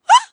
Gasp 3.wav